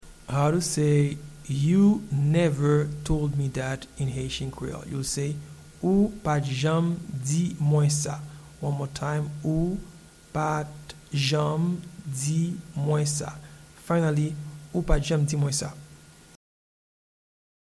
Pronunciation and Transcript:
You-never-told-me-that-in-Haitian-Creole-Ou-pa-t-janm-di-mwen-sa.mp3